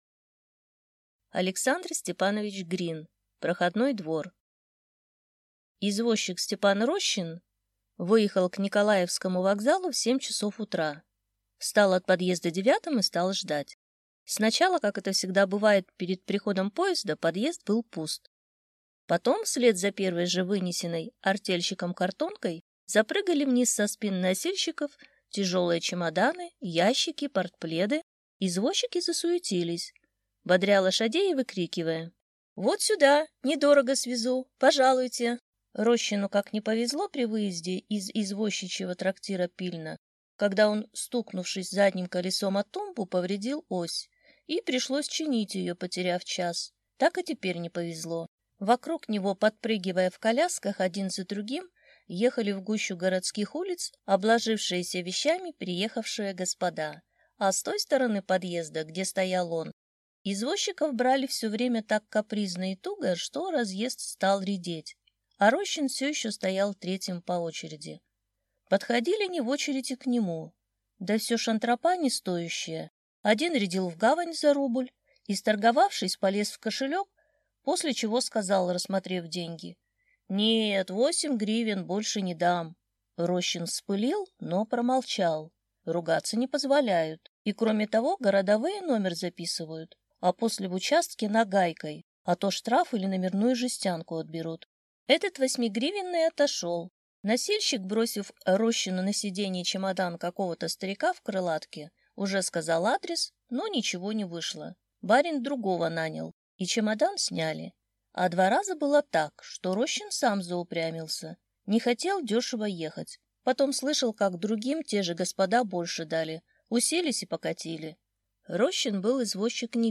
Аудиокнига Проходной двор | Библиотека аудиокниг